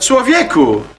Worms speechbanks
missed.wav